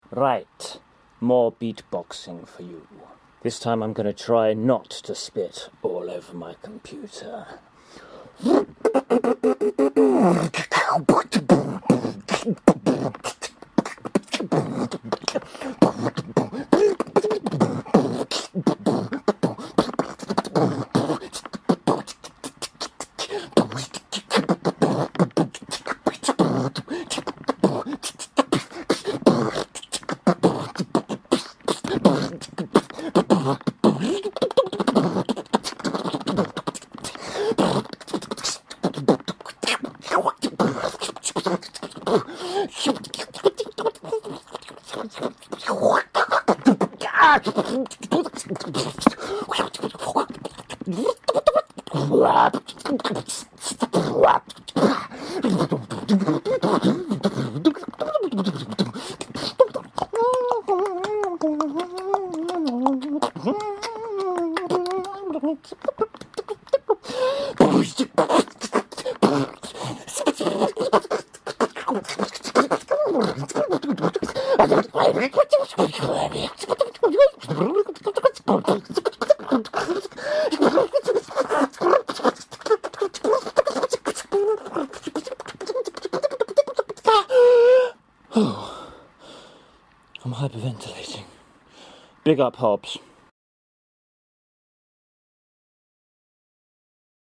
Beatbox 2